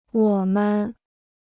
我们 (wǒ men) Free Chinese Dictionary